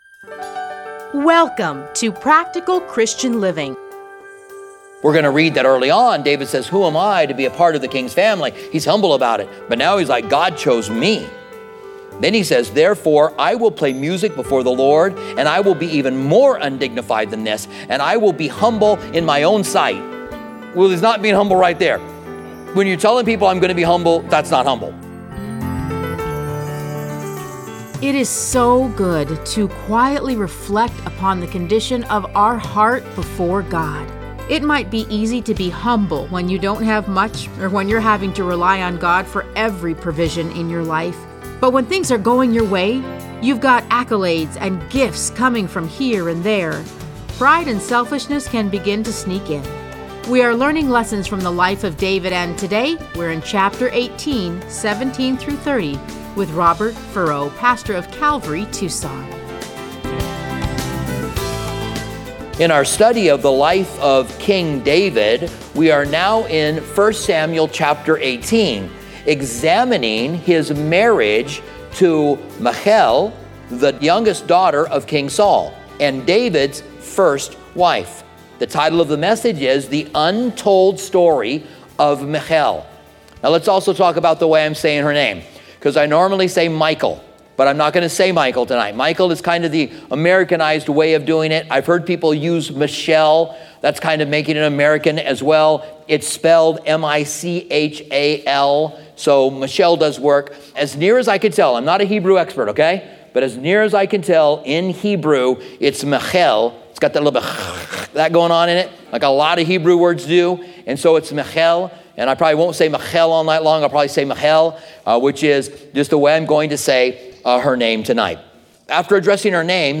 Listen to a teaching from 1 Samuel 18:17-30.